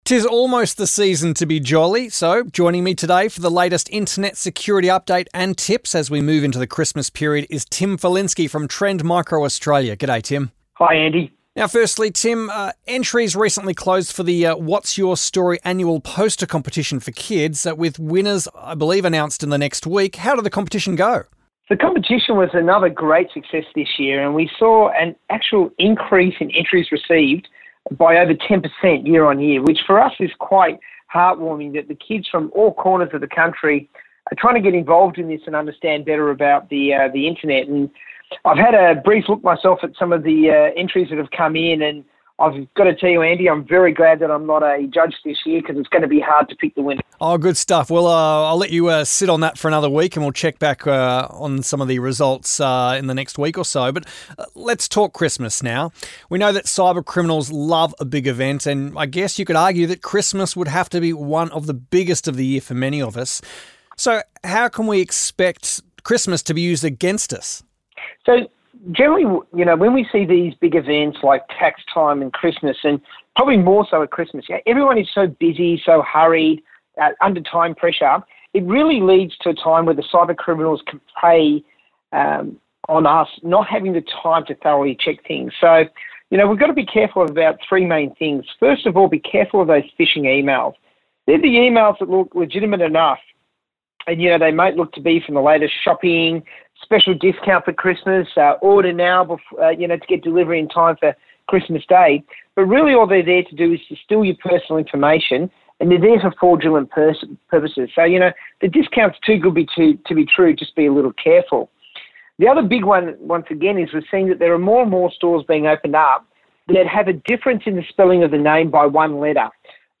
Trend-Micro_November-2018-Security-Update-Interview.mp3